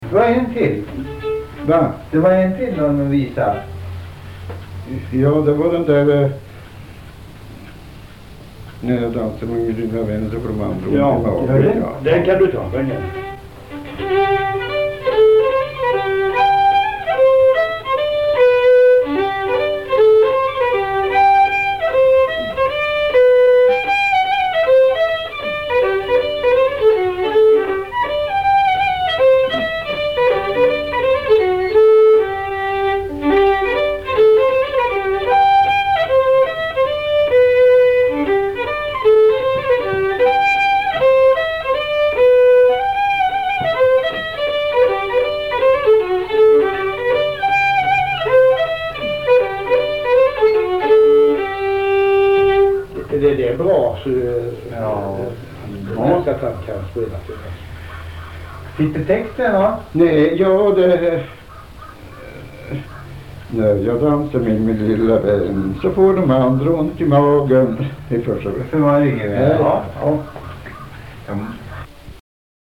intervju
Polska efter morfar – När jag dansar med min lilla vän, då får de andra ont i magen.